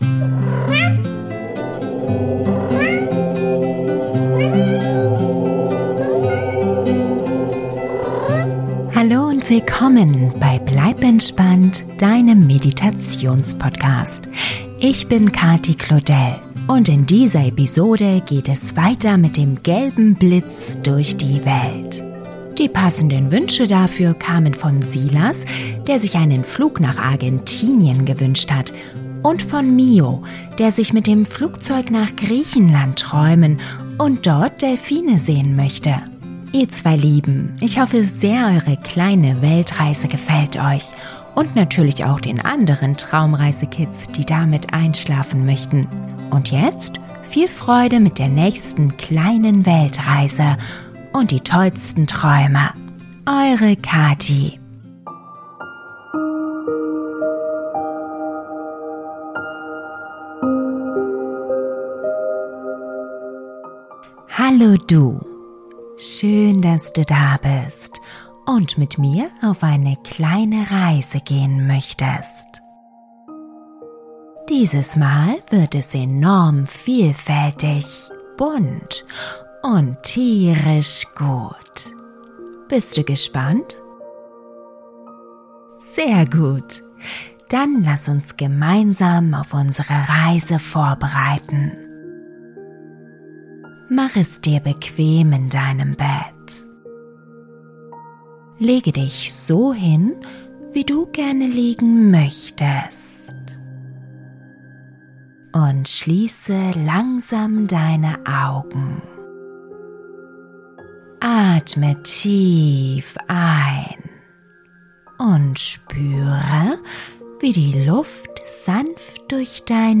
Traumreise für Kinder - Eine kleine Weltreise 5 - Griechenland & Argentinien - Geschichte für Kinder ~ Bleib entspannt!